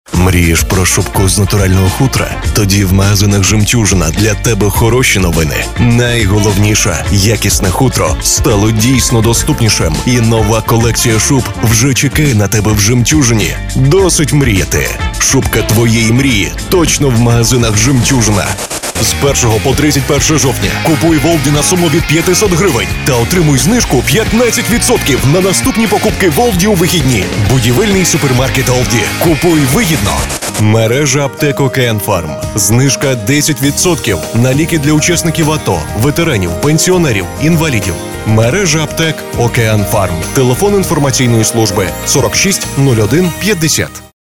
Тракт: дикторская кабина, микрофон Neumann TLM 103, преамп Drawmer MX60, карта Presonus 22VSL
Демо-запись №2 Скачать